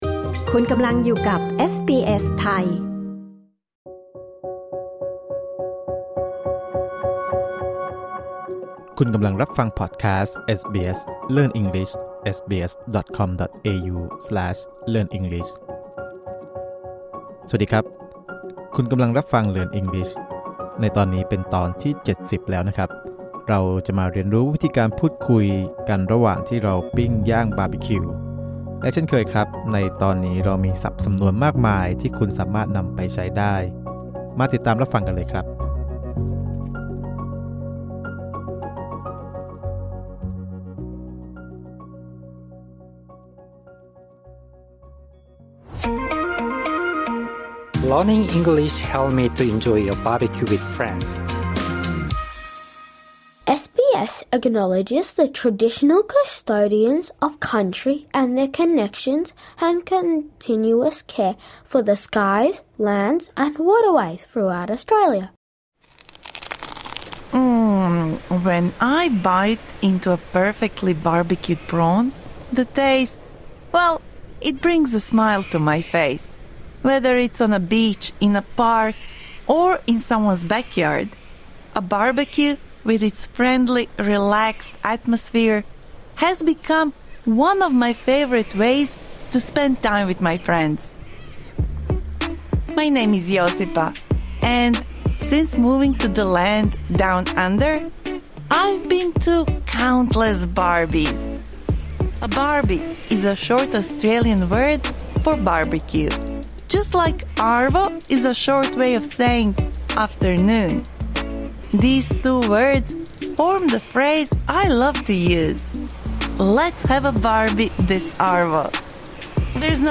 This lesson is suitable for intermediate-level learners.